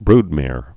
(brdmâr)